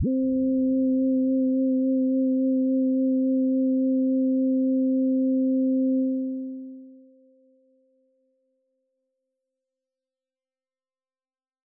Synths